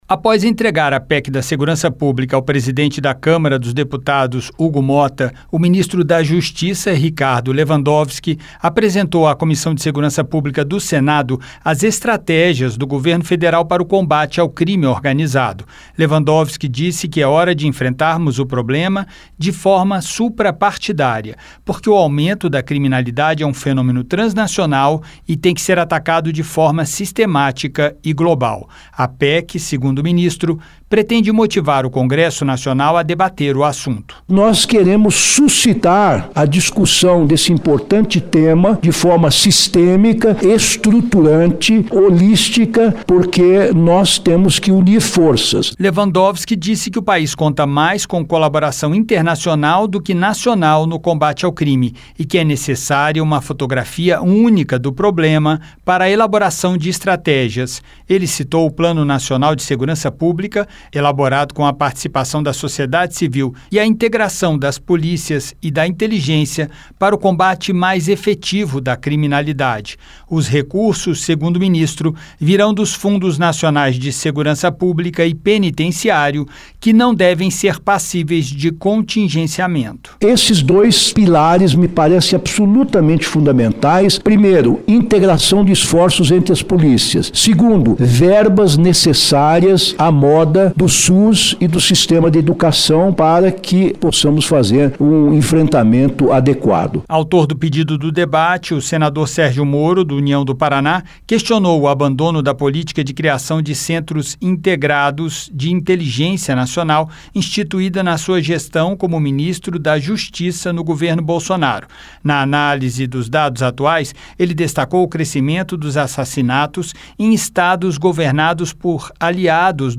O ministro da Justiça, Ricardo Lewandowski, participou nesta quarta-feira (9) de audiência pública promovida pela Comissão de Segurança Pública (CSP). Ele discutiu com os senadores que compõem esse colegiado a chamada PEC da Segurança Pública, que tem entre seus principais objetivos o combate ao crime organizado.